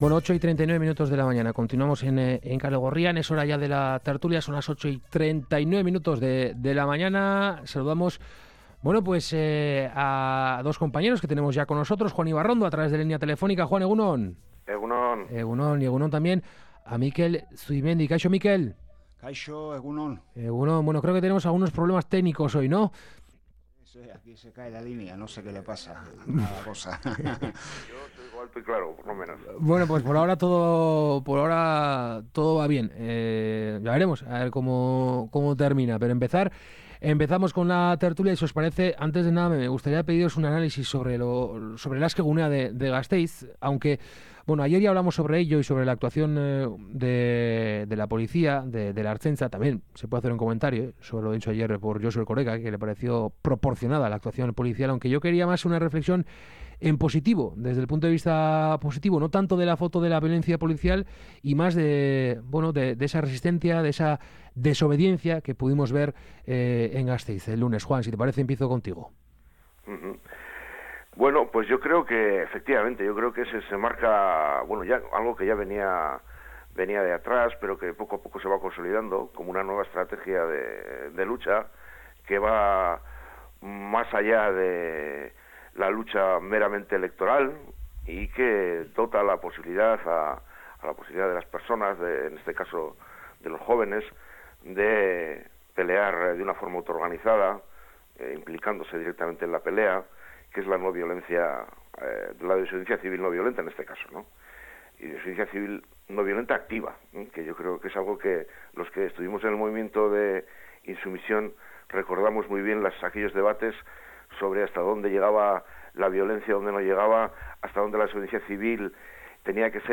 La tertulia de Kalegorrian: herri harresia, operación araña…